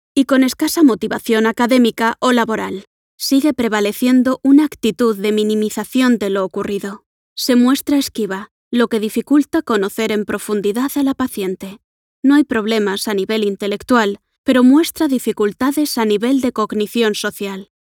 une voix off espagnole lumineuse et captivante – aiguë, vivante et polyvalente – idéale pour les publicités, l'e-learning et la narration.
Narration médicale
Microphone : Neumann TLM 102.